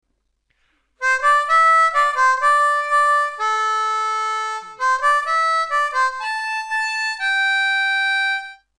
Lee Oskar Melody Maker in G
Middle section